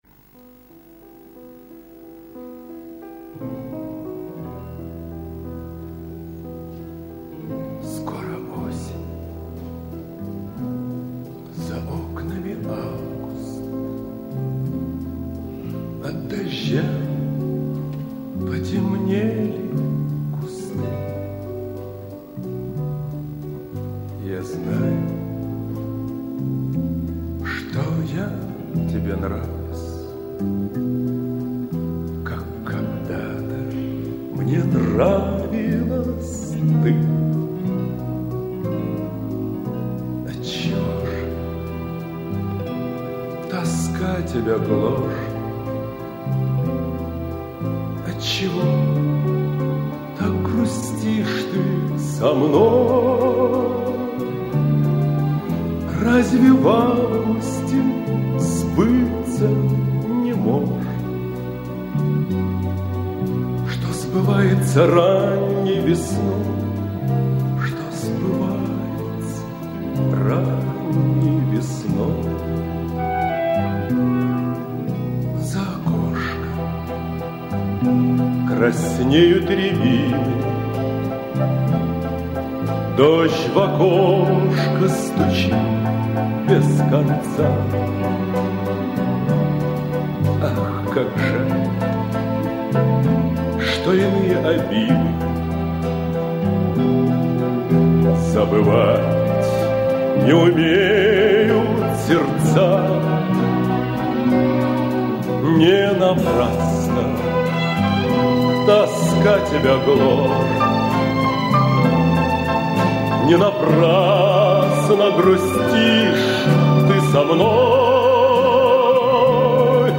в приличном качестве